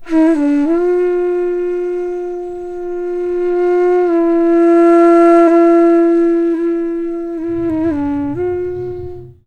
FLUTE-A06 -R.wav